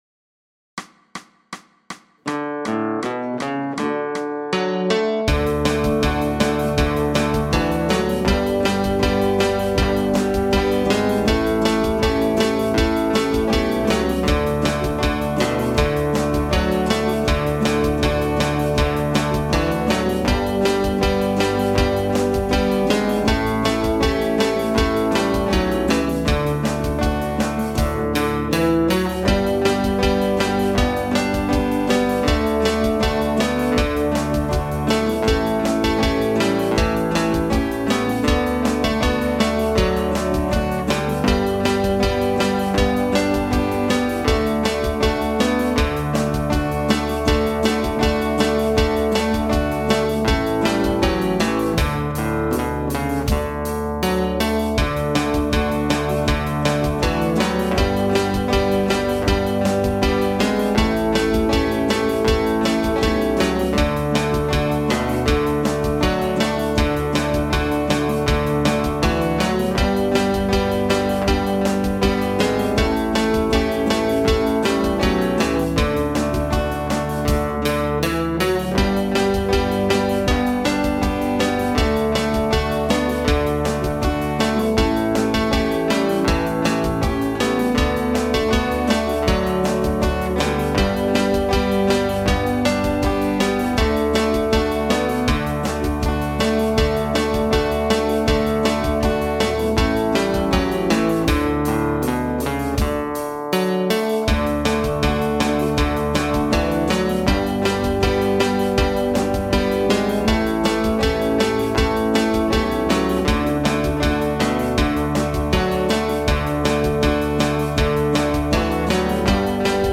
Singe mit der Aufnahme mit!
Das-alte-Haus-Gesang.mp3